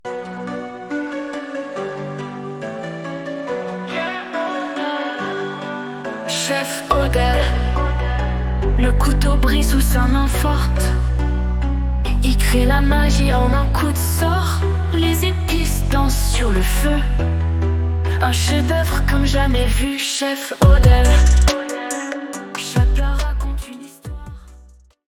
Style : Latino